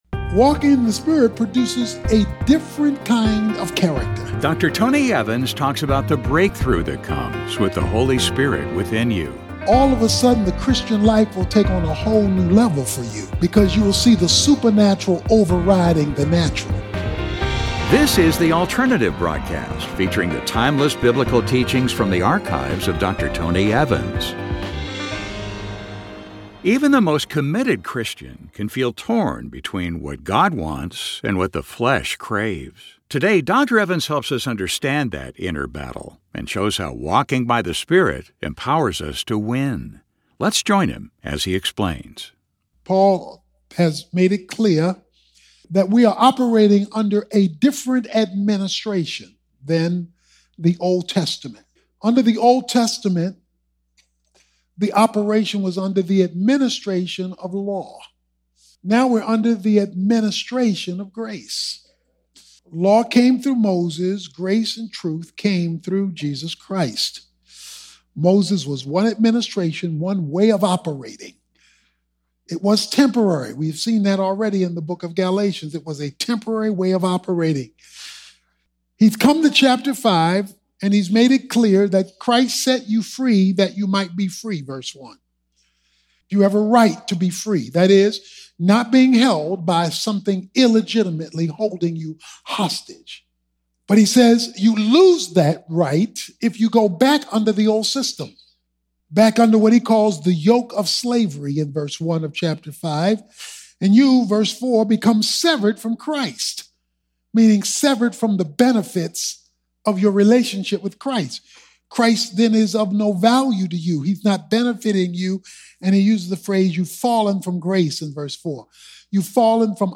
Even the most committed believer can feel pulled between what God desires and what the flesh craves. In this message, Dr. Tony Evans helps us understand that inner conflict and shows how walking by the Spirit empowers us to win.